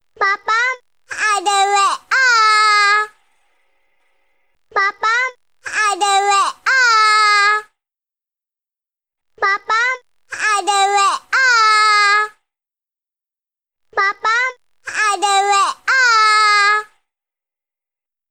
Kategori: Nada dering
Keterangan: Ini adalah nada dering lucu dari bayi yang memanggil papa.